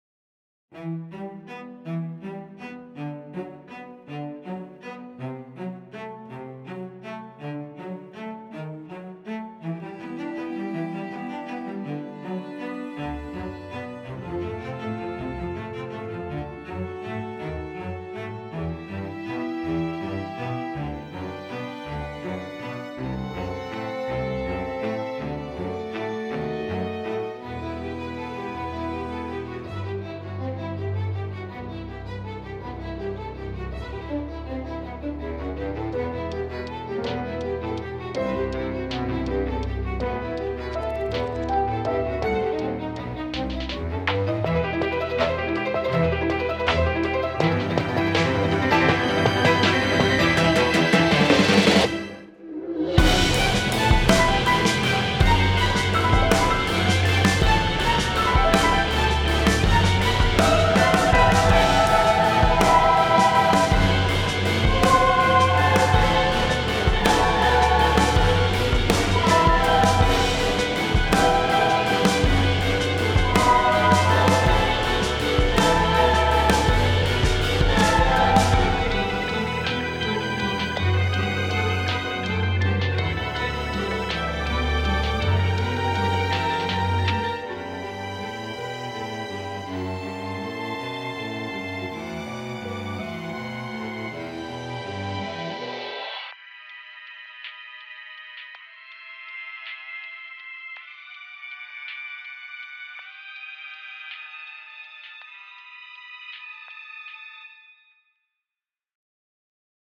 Epic, Action, Suspense - Cinematic / Classical